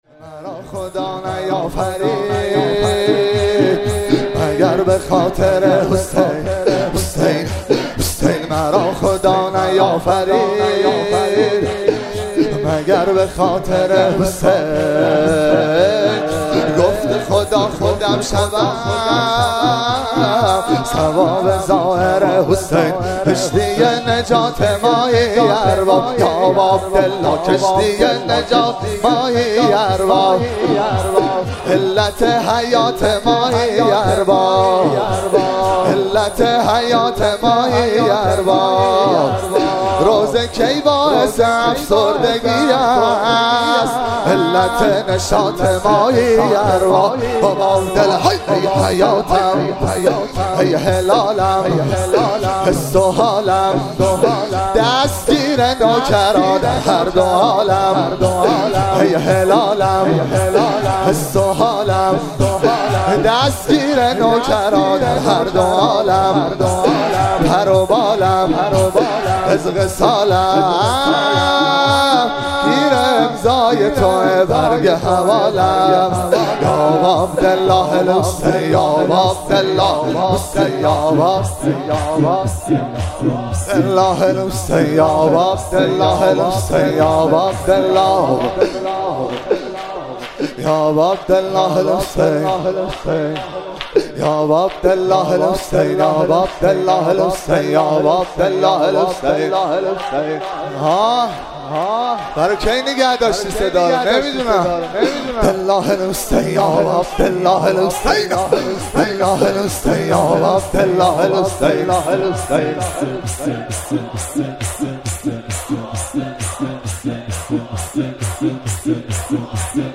مراسم روضه اسارت اهل بیت (ع)- مرداد 1401
شور- مرا خدا نیافرید مگر به خاطر حسین